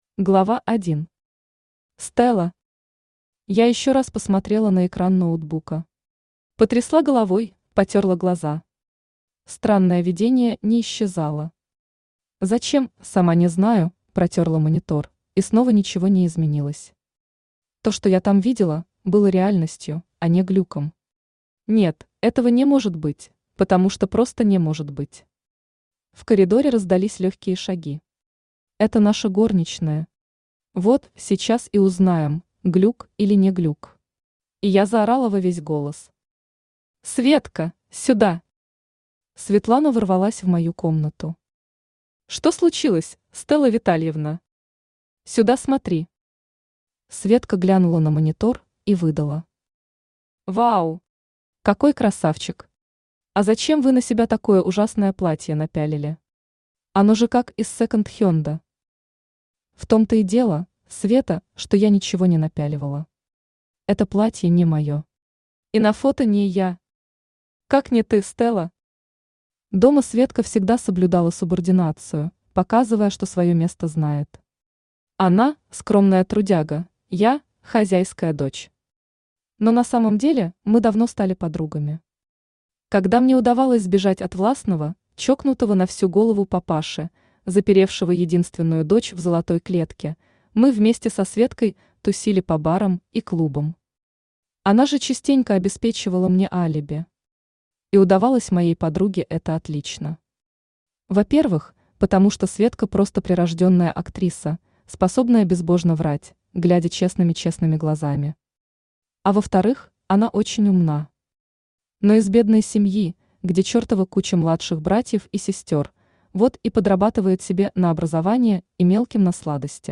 Аудиокнига Девственница для алмазного короля | Библиотека аудиокниг
Aудиокнига Девственница для алмазного короля Автор Лилия Тимофеева Читает аудиокнигу Авточтец ЛитРес.